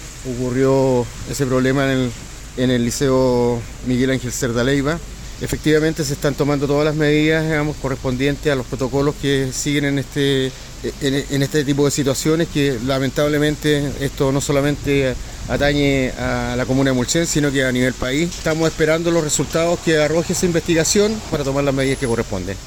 Frente a ello, el alcalde de Mulchén, José Miguel Muñoz, entregó detalles y dijo que la situación ya está siendo investigada para “tomar las medidas que corresponden”.
cuna-alcalde-mulchen.mp3